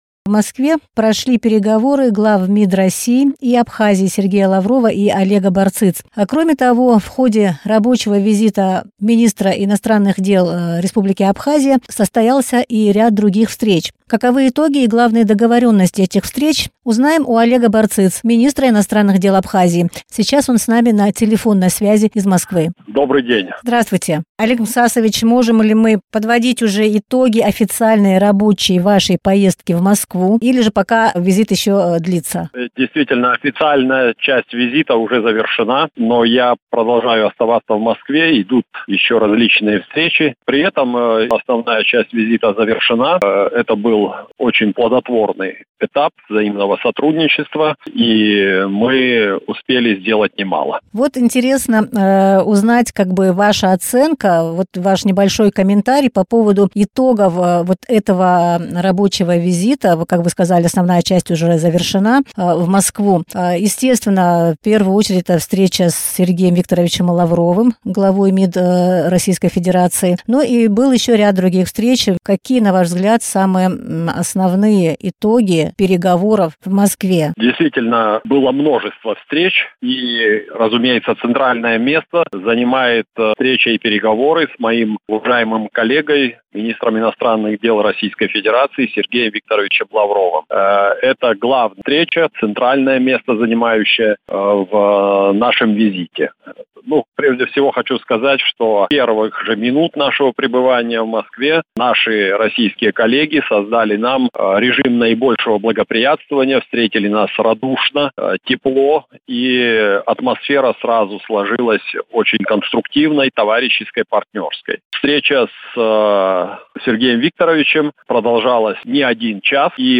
Вопросам социально-экономического развития и сотрудничества Абхазии с Россией придали большое значение на переговорах с главой МИД РФ Сергеем Лавровым, сообщил в эфире радио Sputnik Олег Барциц. Он подчеркнул, что Россия всячески содействует устойчивому развитию Абхазии.